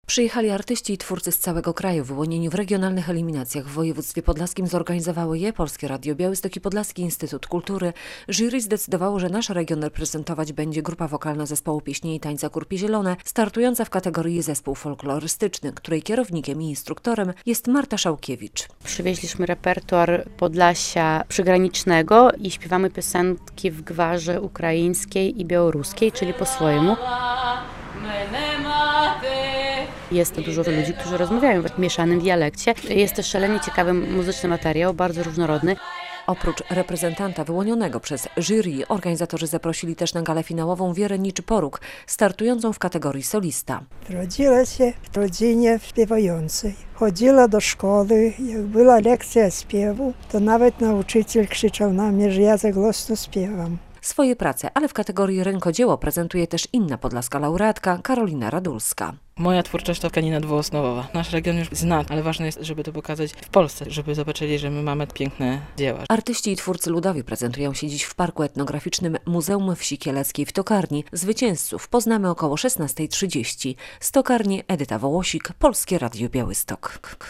Na niedzielę zaplanowano prezentacje przedstawicieli poszczególnych województw, a także wręczenie nagród - w Tokarni jest nasza reporterka